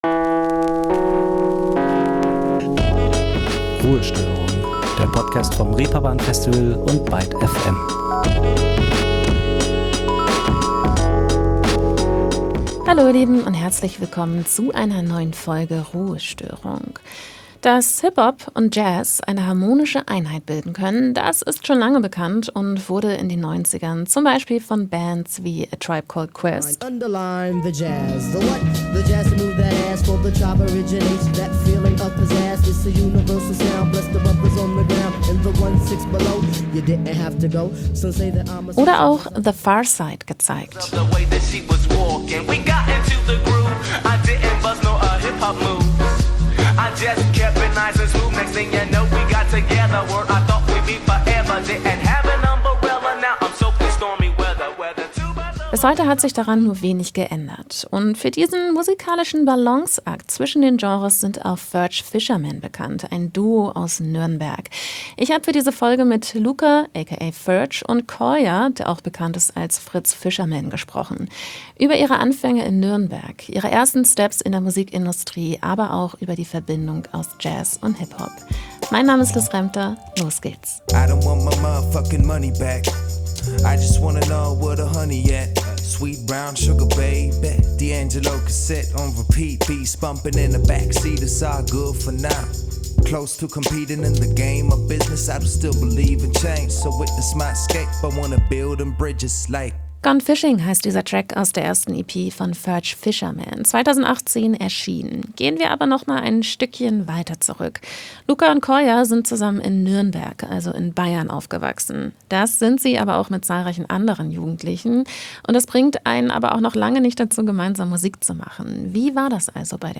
Ruhestörung - der Podcast zu Live Musik und Musik-Kultur vom Reeperbahn Festival und ByteFM.